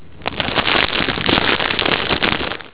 fire.au